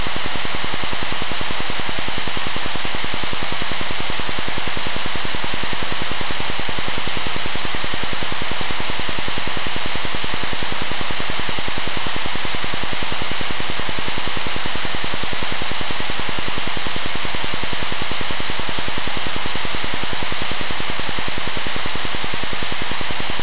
Dab+.mp3